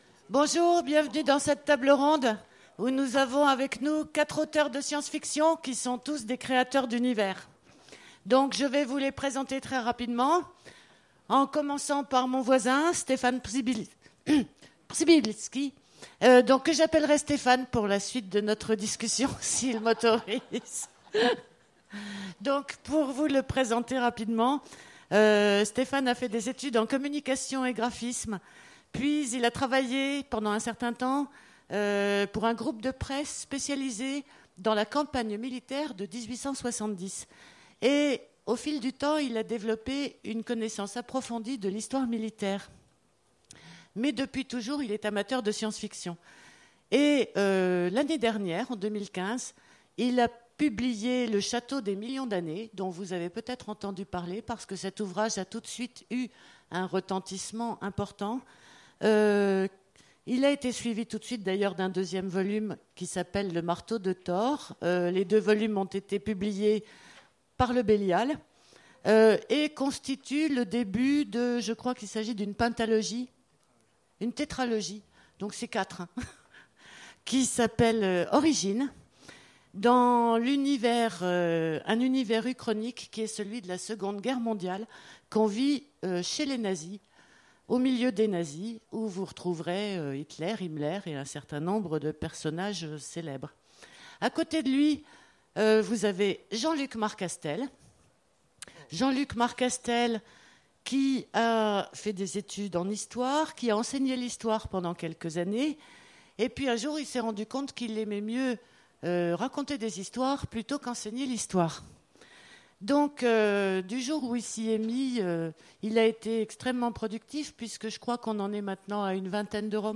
Imaginales 2016 : Conférence Auteurs de science-fiction…